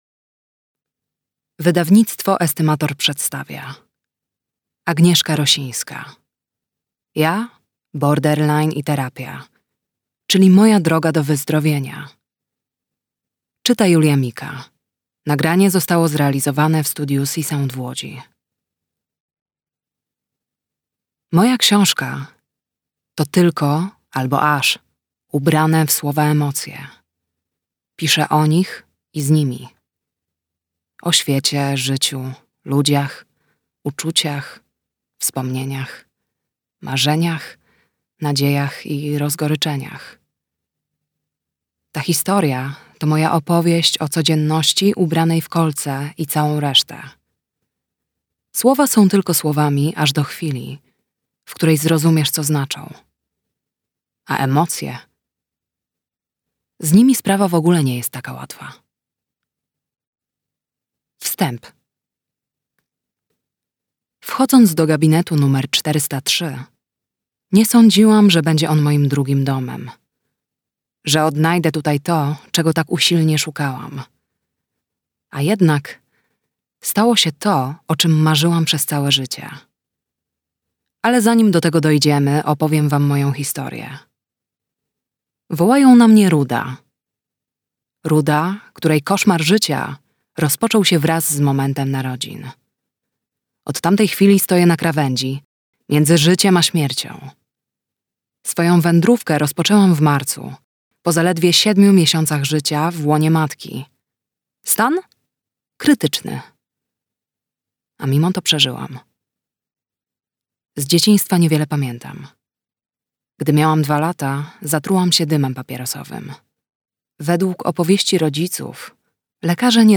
[Audiobook]